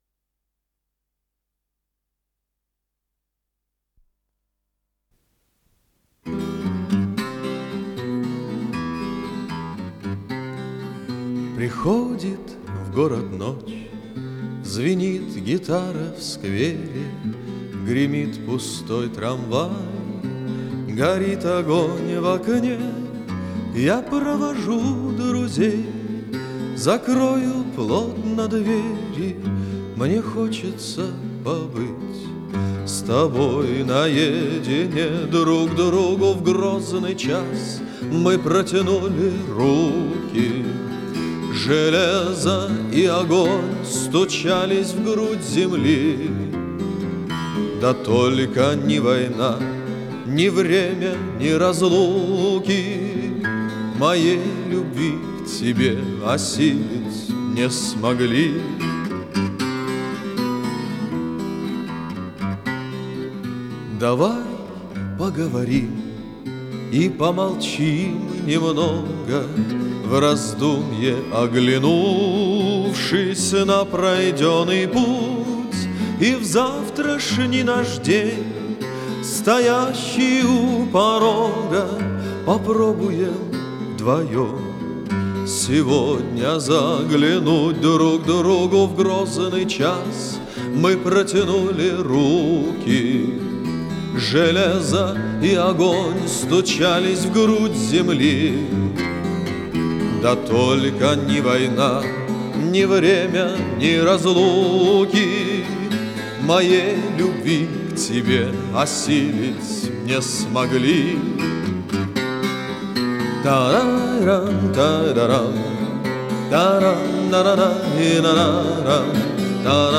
с профессиональной магнитной ленты
пение в собственном сопровождении на 12-струнной гитаре
Скорость ленты38 см/с
ВариантДубль моно